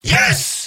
Robot-filtered lines from MvM. This is an audio clip from the game Team Fortress 2 .
{{AudioTF2}} Category:Demoman Robot audio responses You cannot overwrite this file.
Demoman_mvm_yes03.mp3